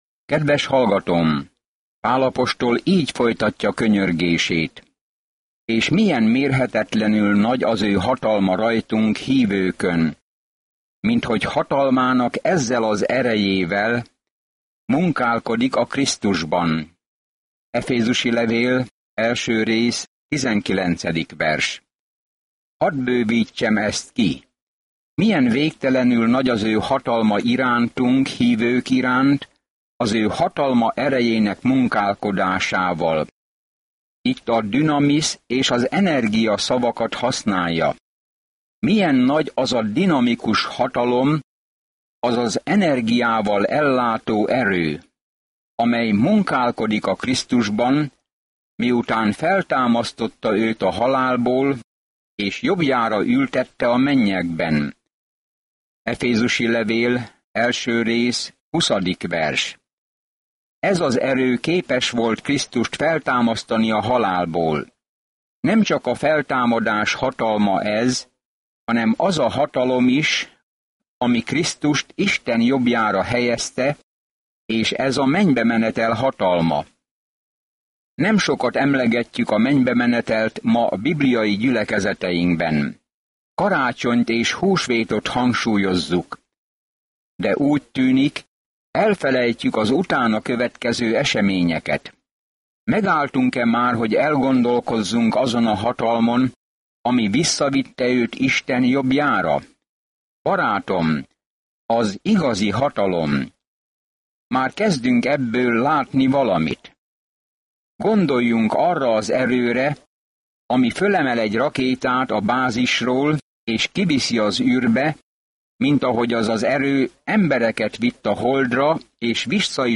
Szentírás Efezus 1:19-23 Efezus 2:1-3 Nap 8 Olvasóterv elkezdése Nap 10 A tervről Az efézusiakhoz írt levél elmagyarázza, hogyan kell Isten kegyelmében, békéjében és szeretetében járni, a csodálatos magasságokból, hogy mit akar Isten gyermekei számára. Napi utazás az efézusi levélben, miközben hallgatod a hangos tanulmányt, és olvasol válogatott verseket Isten szavából.